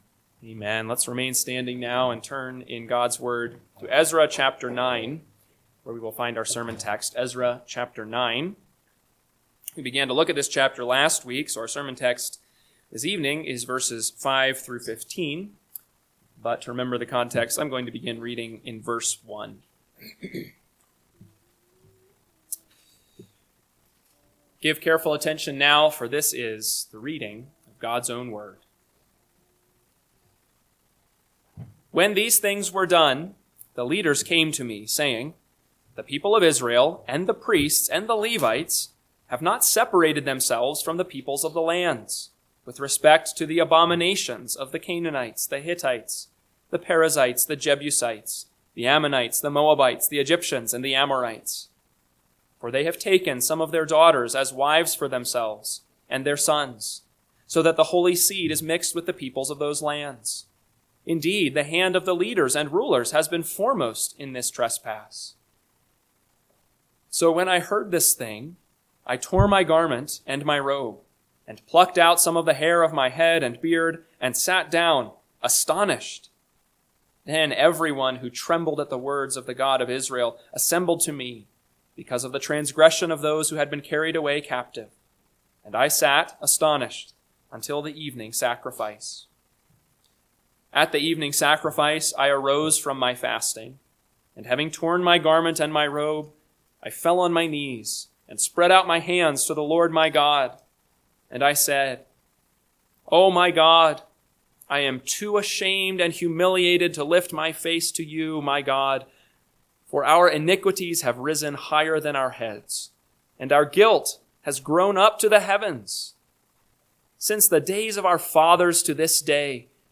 PM Sermon – 5/25/2025 – Ezra 9:5-15 – Northwoods Sermons